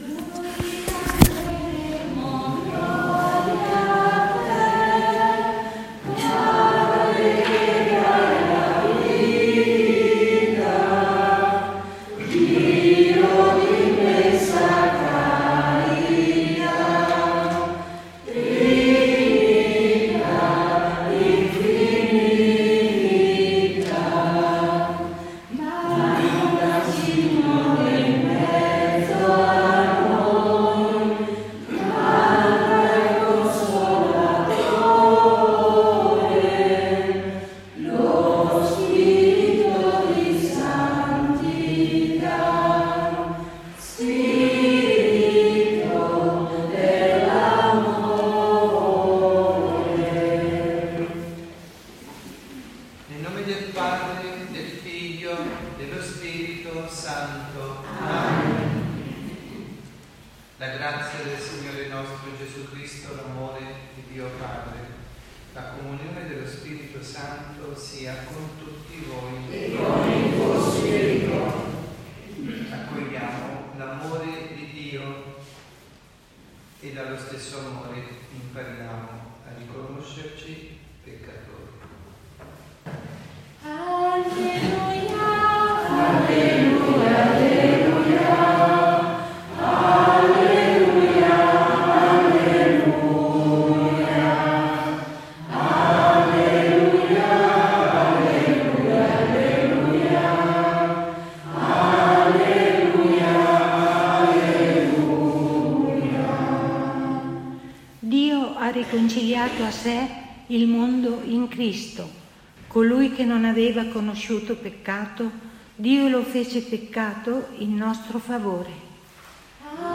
Omelia
dalla Parrocchia S. Rita – Milano